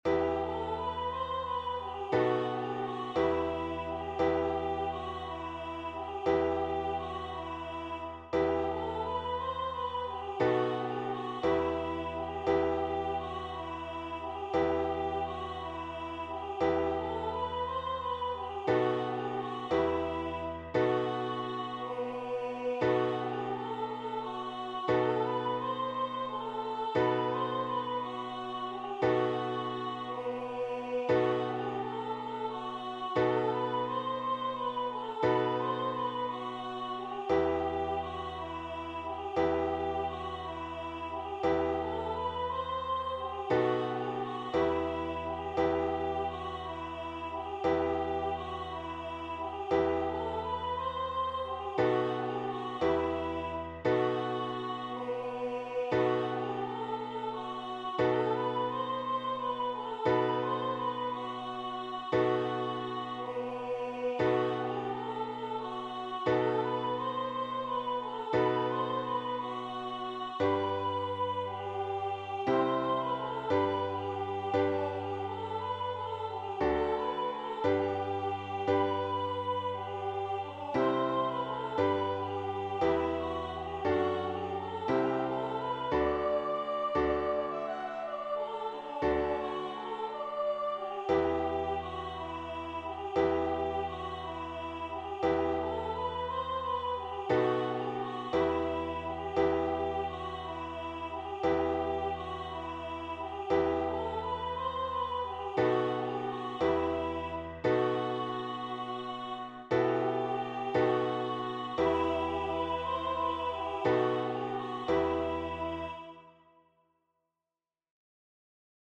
a welcoming opening hymn